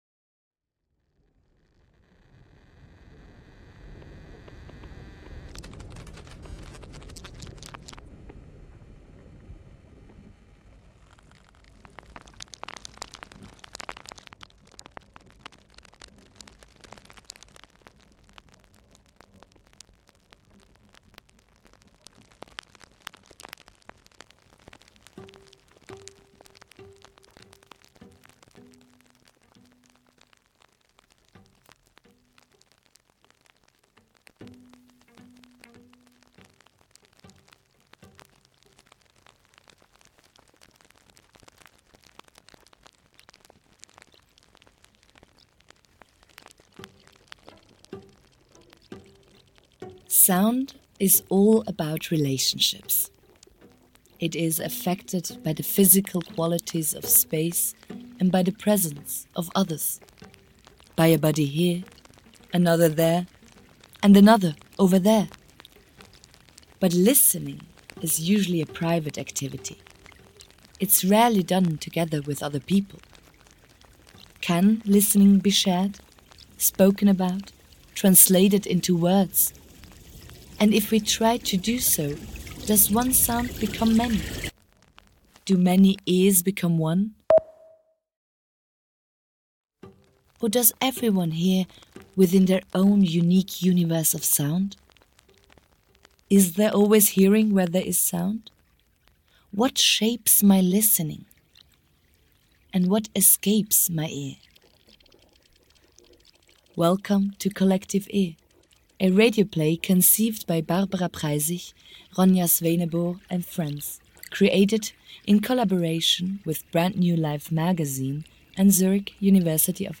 Four Radio Plays for Deep Listeners, 2025